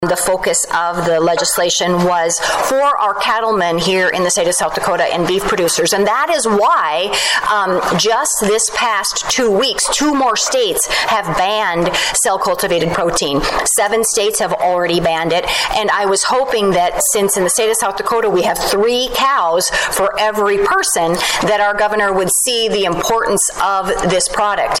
District 18 Legislative Cracker Barrel Held in Yankton